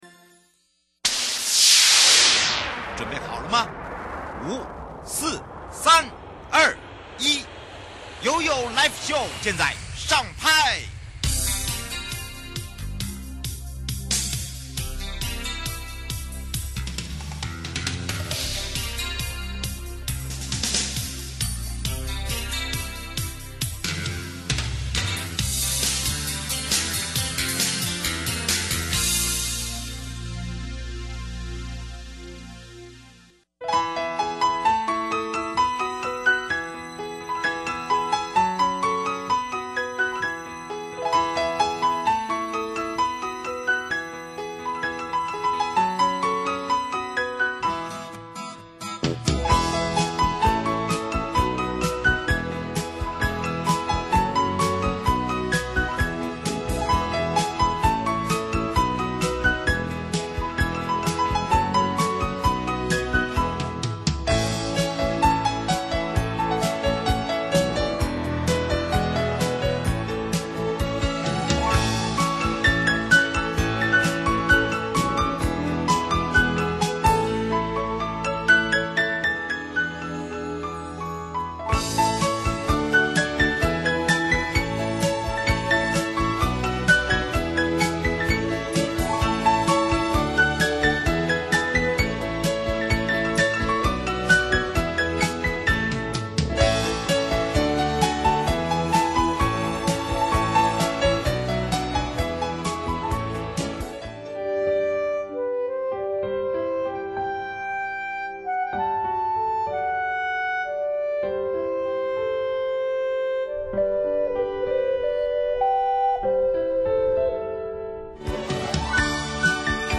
受訪者： 1.營建你我他 快樂平安行 七嘴八舌講清楚-台中市政府如何改善通學路徑交通及景觀?如何打造學校周邊無